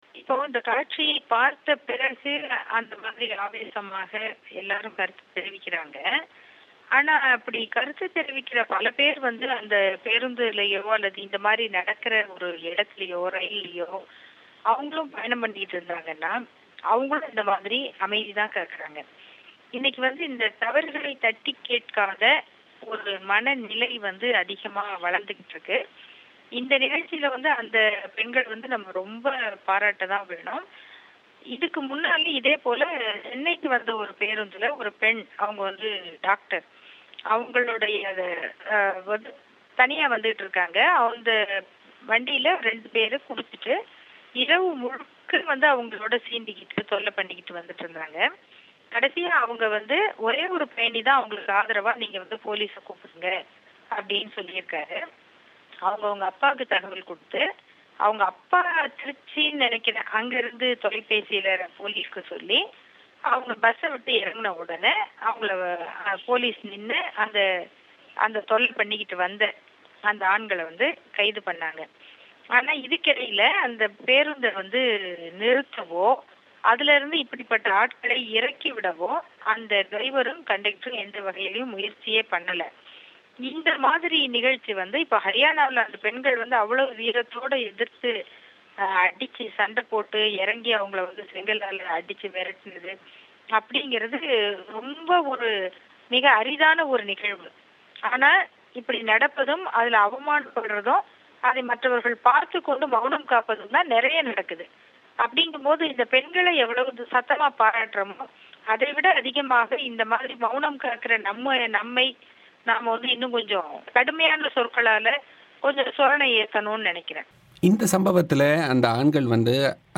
வழங்கிய செவ்வி.